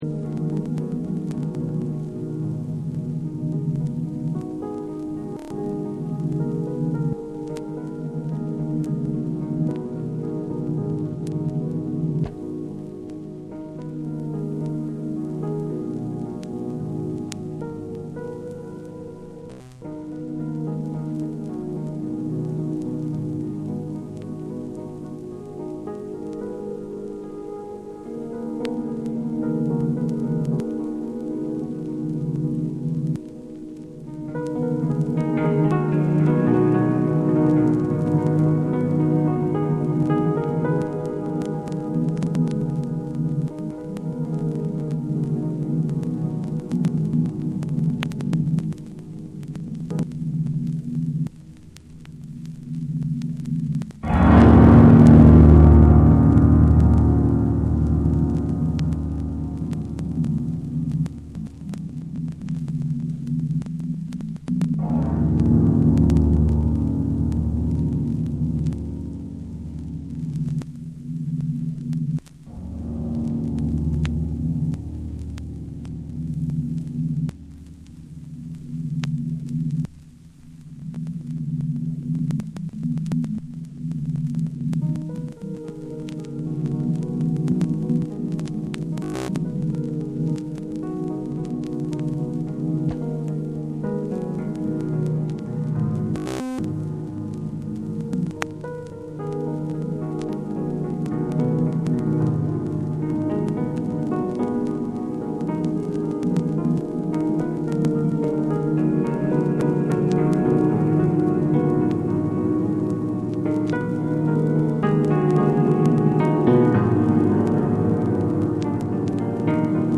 JAPANESE / NEW AGE